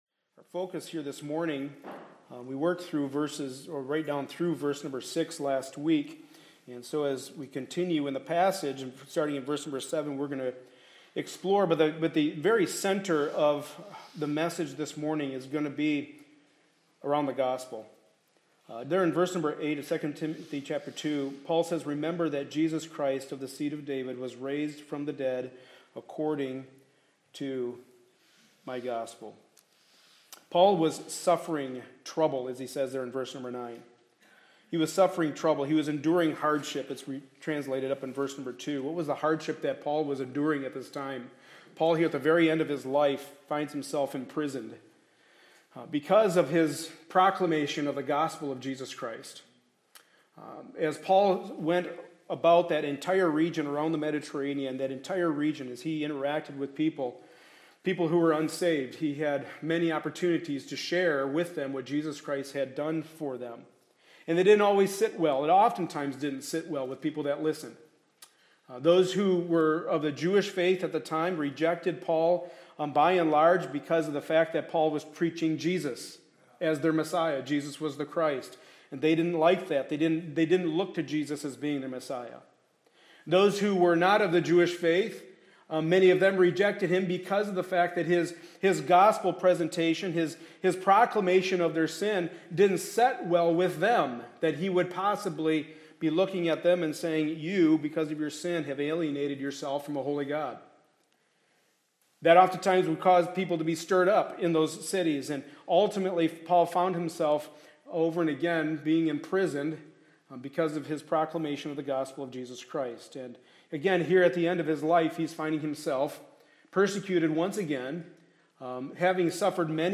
2 Timothy 2:8 Service Type: Sunday Morning Service Related Topics